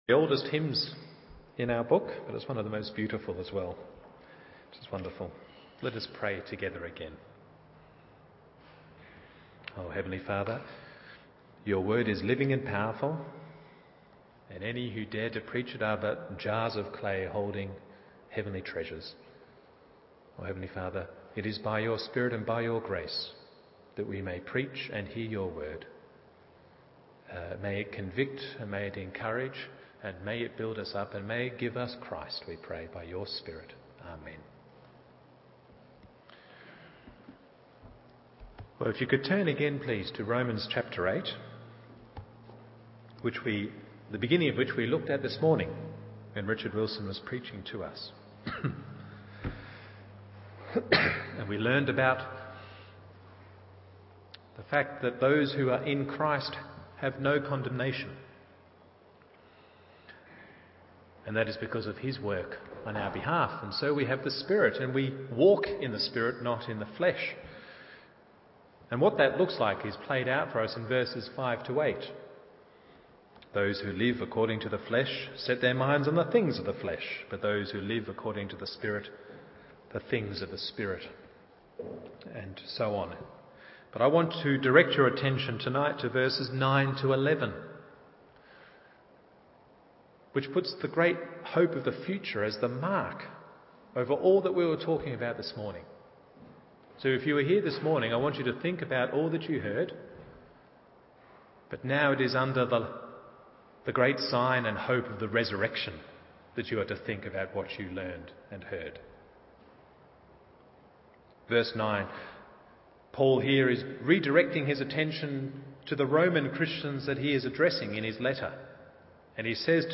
Evening Service Romans 8:9-11 1. You are not in the flesh 2. You have life in Christ 3. You enjoy hope of resurrection…